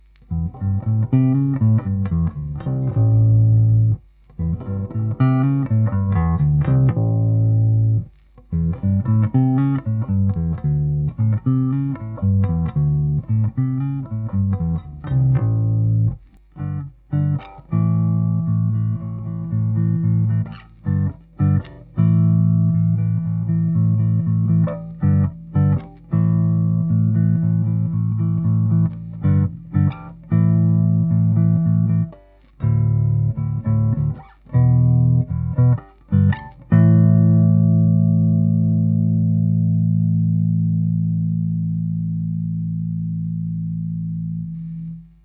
A 51 Precision bass (Telecaster bass) pickup.
This gives it a sweeter top end and softer attack.
Also overdrives and cuts through well due to the extra midrange girth.